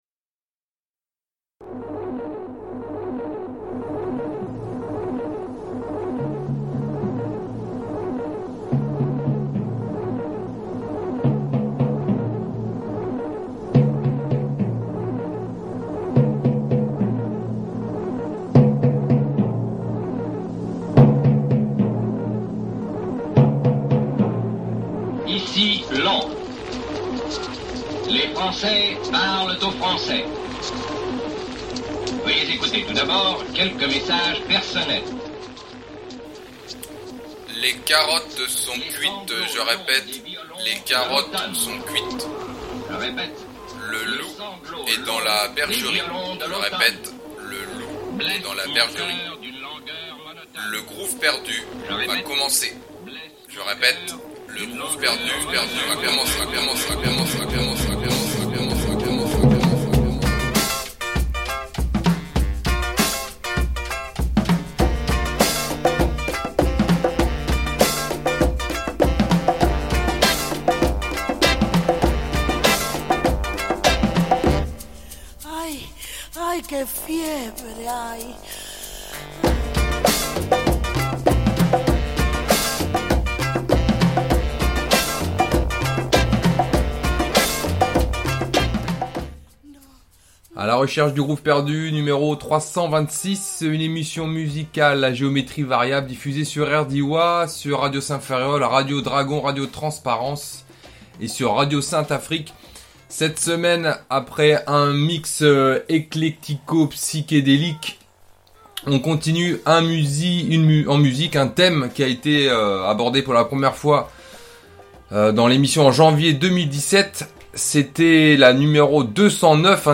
funk , hip-hop , jazz , musique de film